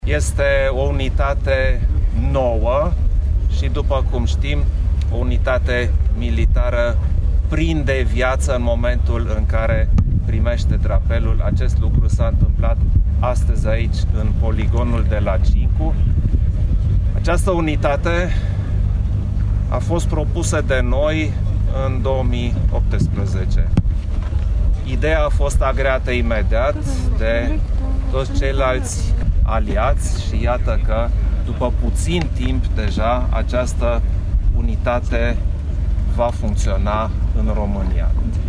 Președintele României, Klaus Iohannis: